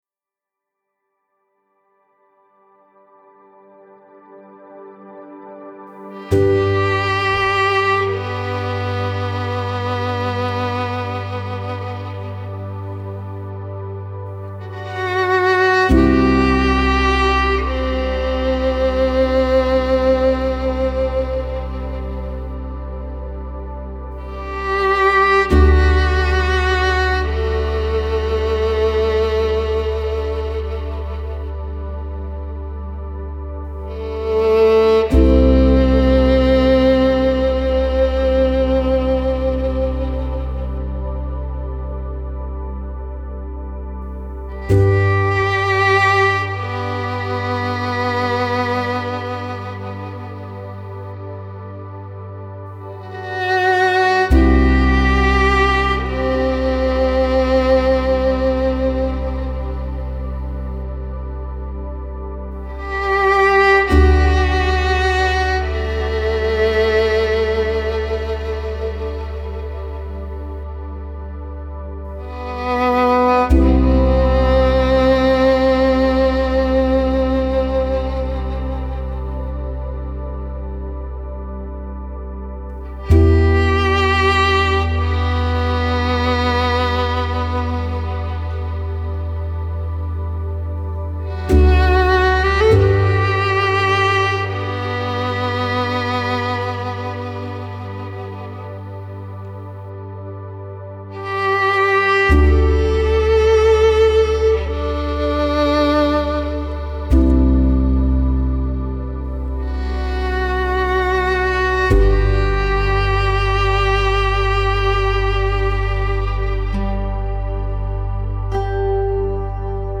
آرامش بخش , موسیقی بی کلام , ویولن
موسیقی بی کلام ویولن